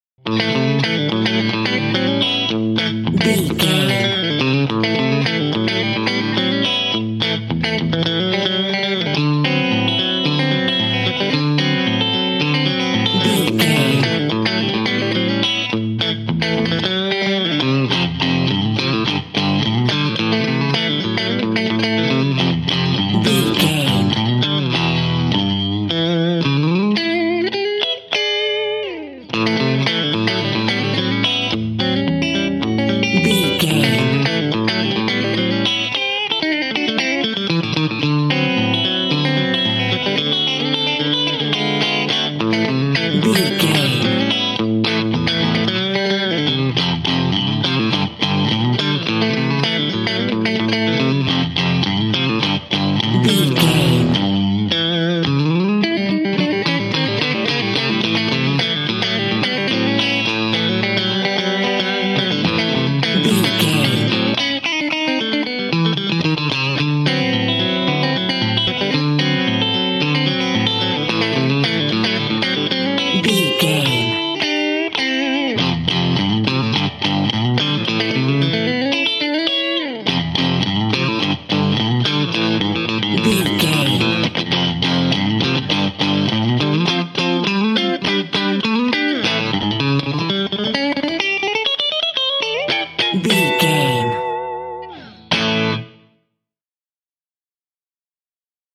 Good times, relaxing, friends, party,
Mixolydian
electric guitar
bass guitar